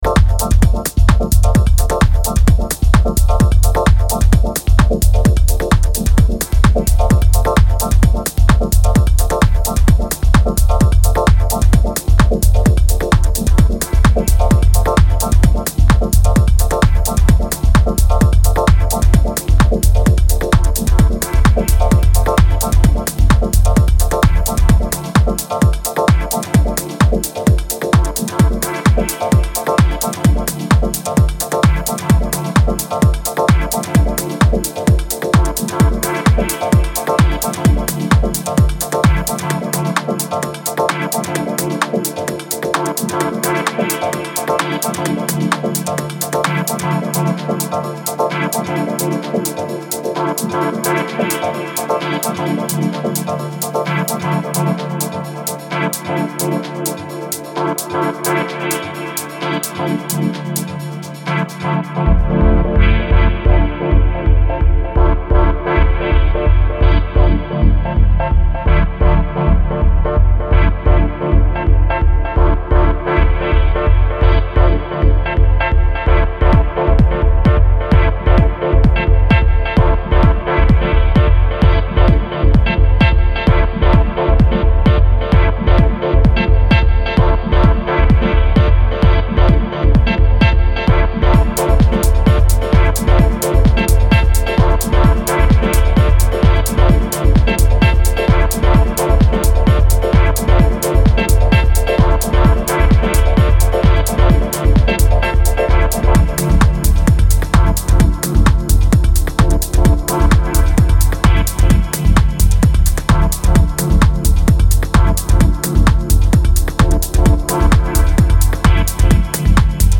Deep and Dub Techno